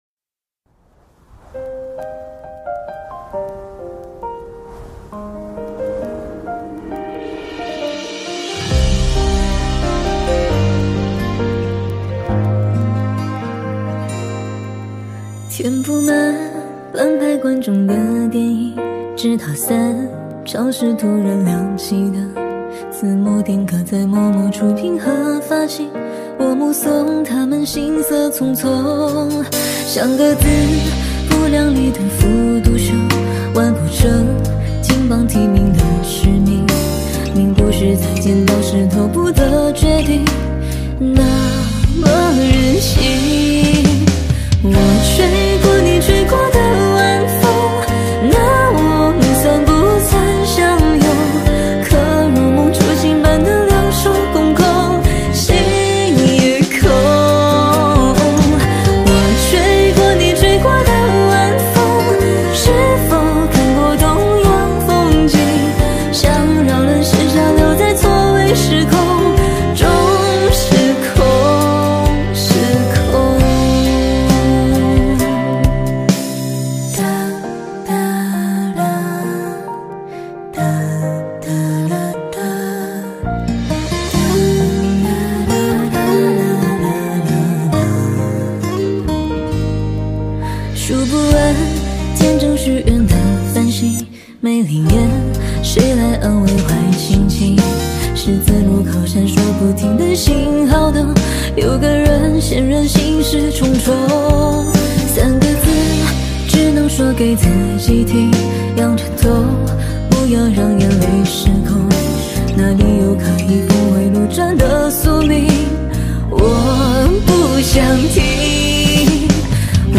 治愈版